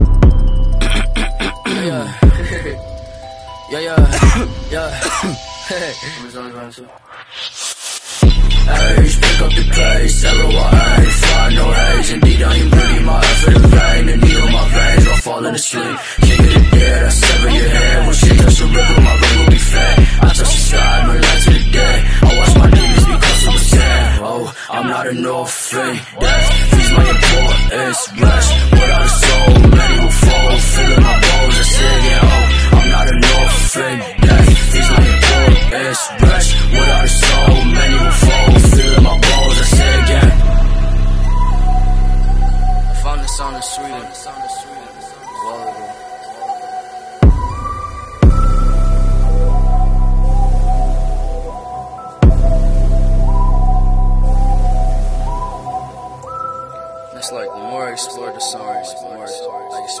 I let him get a head start and shifted without looking based on the sound of the engine